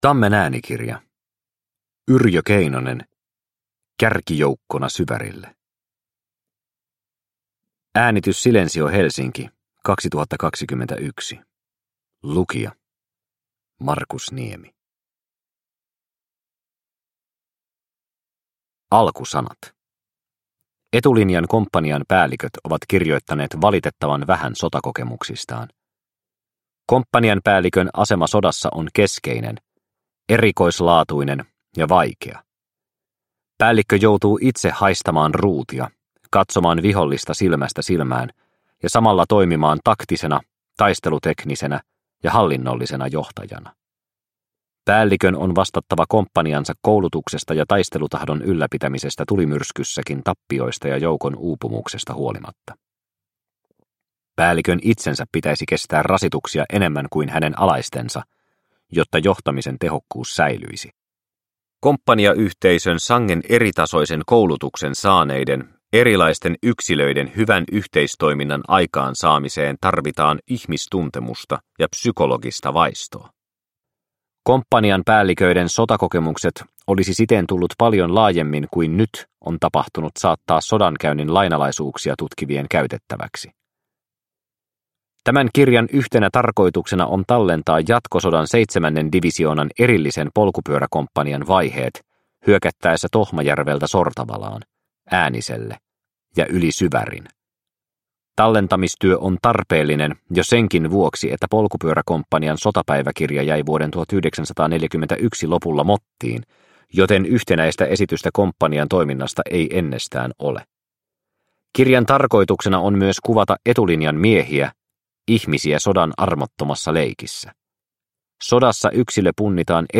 Kärkijoukkona Syvärille – Ljudbok – Laddas ner